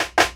Dousb_Doub_snr.wav